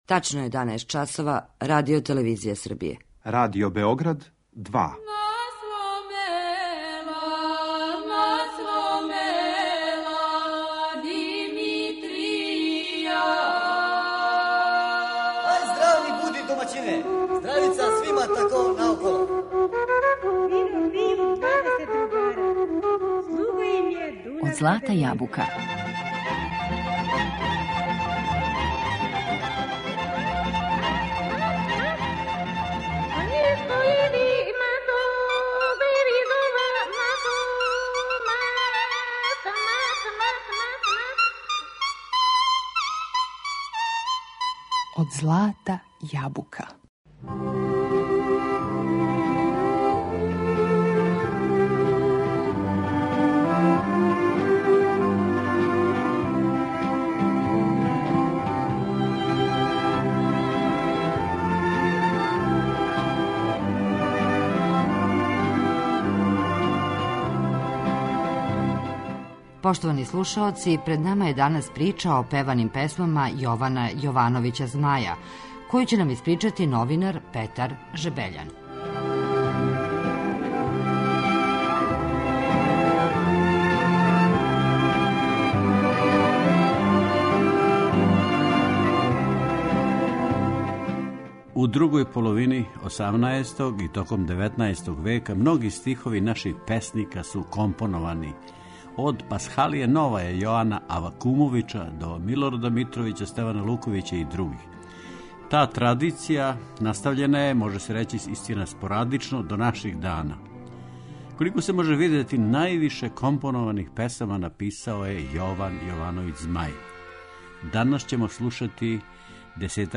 Народне песме испеване на Змајeве стихове
У емисији слушамо народне песме које су испеване на Змајeве стихове.